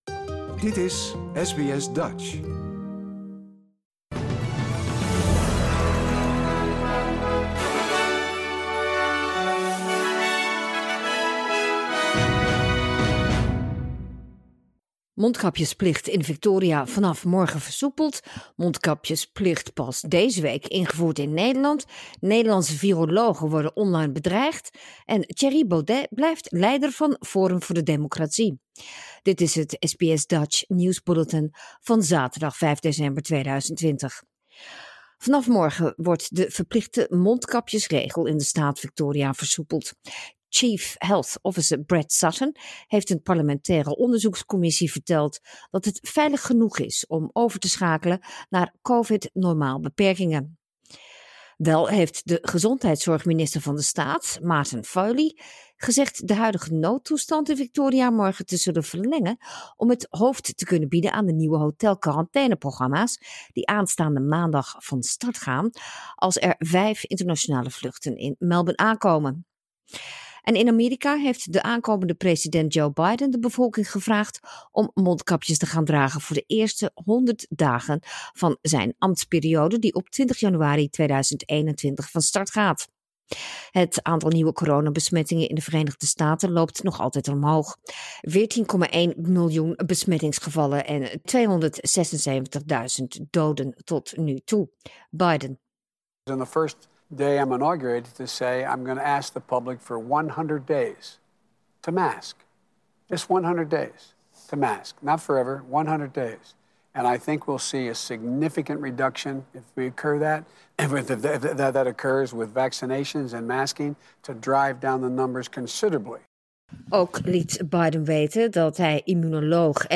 Nederlands/Australisch SBS Dutch nieuwsbulletin zaterdag 5 december 2020